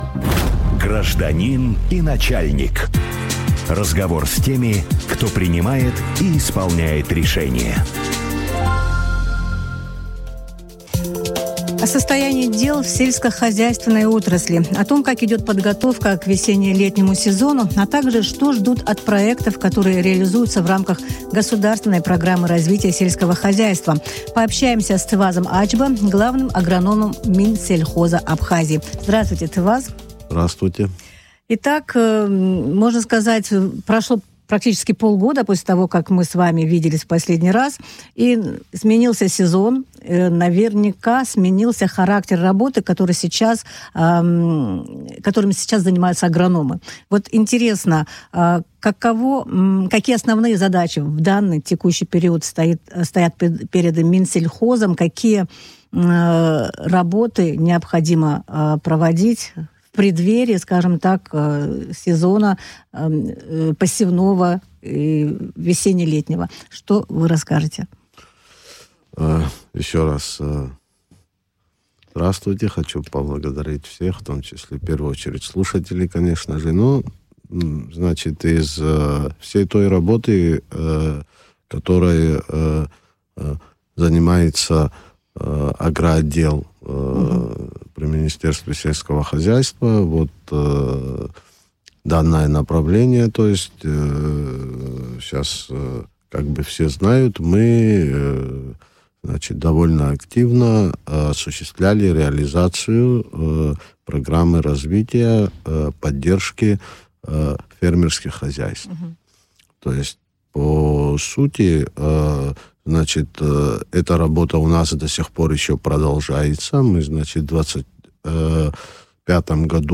О задачах, стоящих перед Минсельхозом весной, в эфире радио Sputnik рассказал